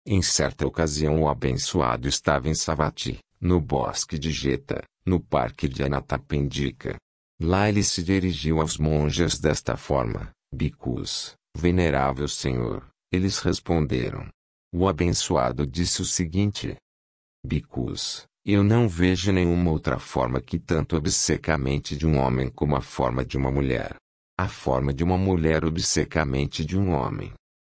Ricardo -20%
Just listening: Ricardo is a beautiful voice, and he will also accommodate for users with high frequency hearing loss.